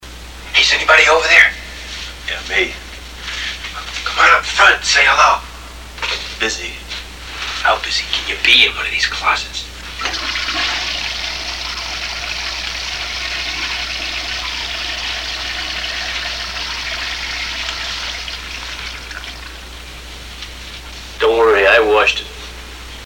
After the sound of a toilet flush, Morris extends his hand through the bars for a handshake.